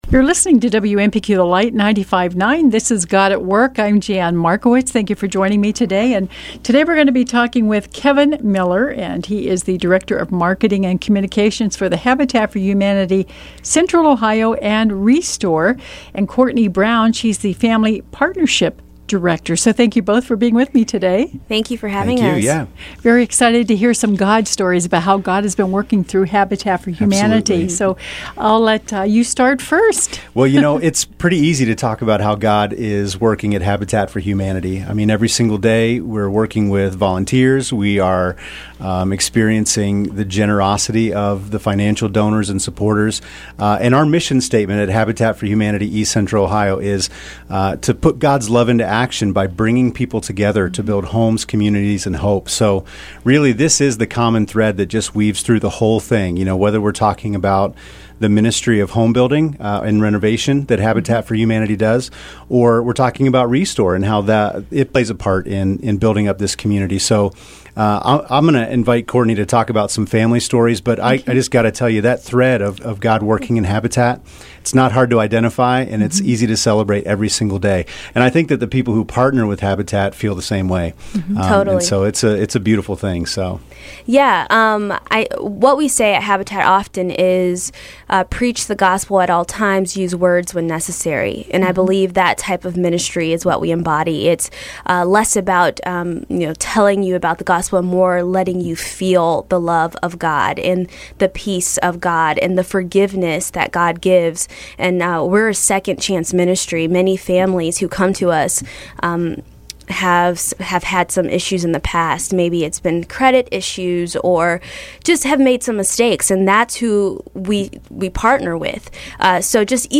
We’re broadcasting the mission of Habitat for Humanity and Habitat for Humanity ReStore over the radio airwaves! Tune in to this God at Work interview.